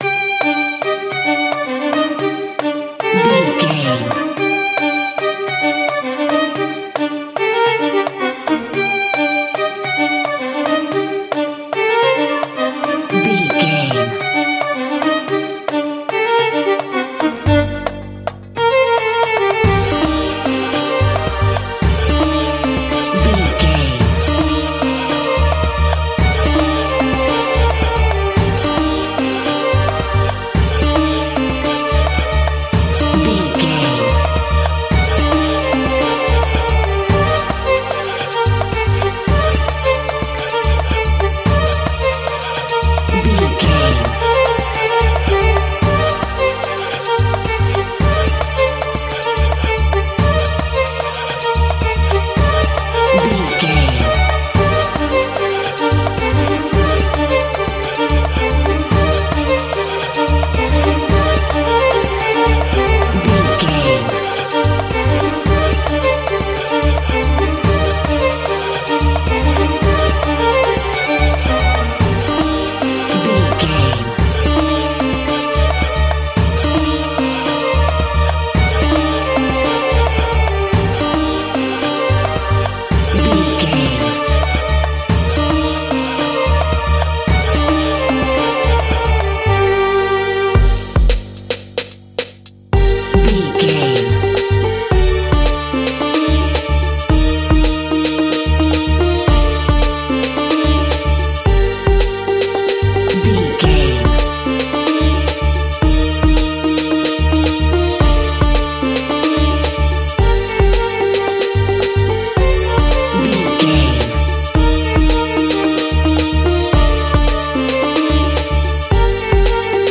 Aeolian/Minor
World Music
percussion
bongos
djembe